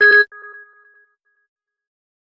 HTC Bildirim Sesleri
Ses çözünürlüğü 192 Kbps / 16 bits ve ses örnekleme oranı 48 Khz olarak üretilen sesler stereo ve yüksek ses çıkışına sahiptir.
Flip Phone
flip_phone.mp3